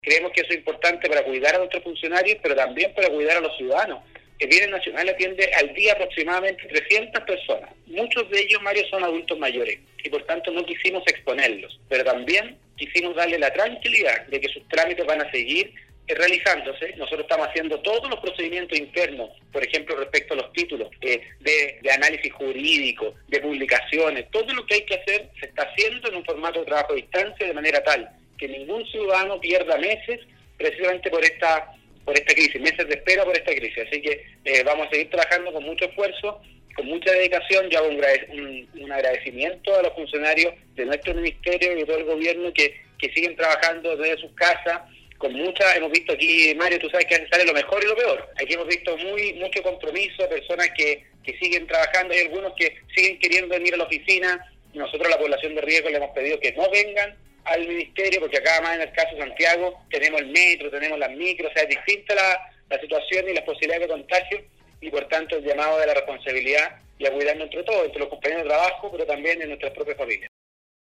La mañana de este miércoles el Ministro de Bienes Nacionales, Julio Isamit, sostuvo un contacto telefónico en el programa Al Día de Nostálgica donde se refirió a la importancia del autocuidado para evitar la propagación del Covid-19.